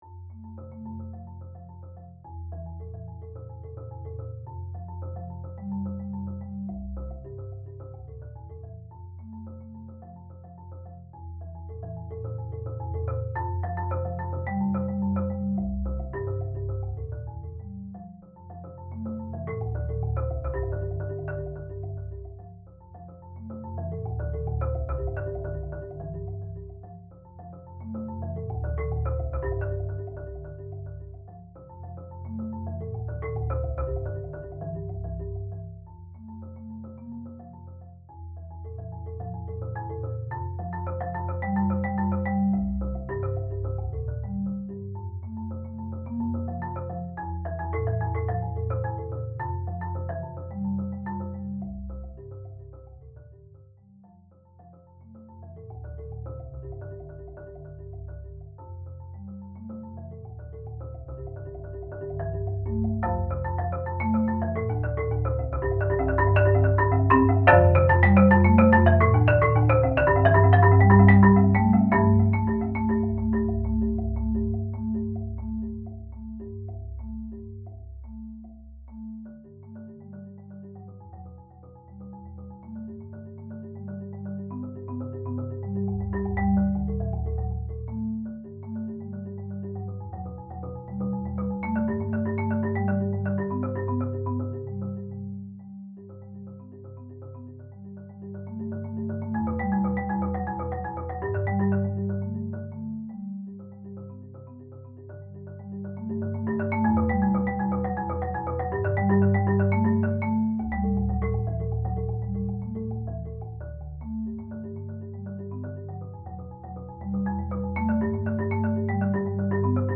• Instrumentation: Solo marimba
• (Low C)